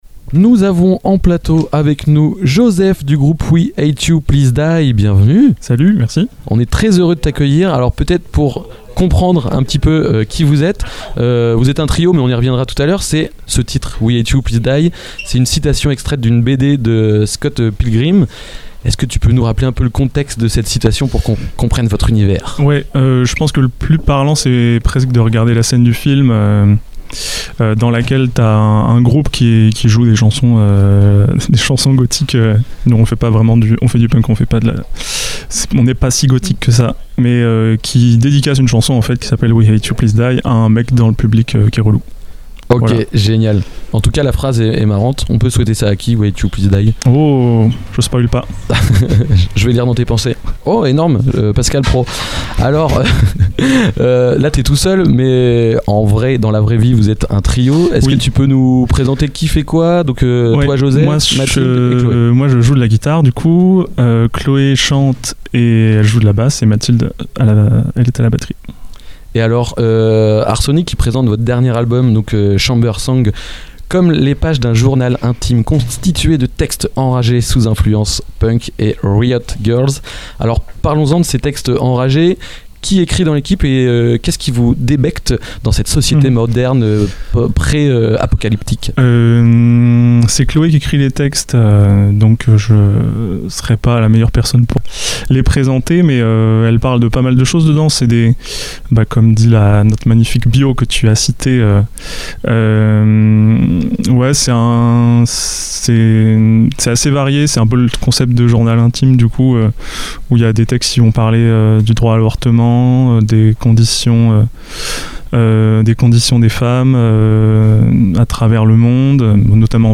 Il revient sur l’identité du groupe, son processus créatif et l’importance du live dans leur démarche artistique. Une interview sincère et énergique qui plonge au cœur de l’univers du groupe et capture toute l’intensité de leur passage sur la scène d’Art Sonic. festival La Musicale Art Sonic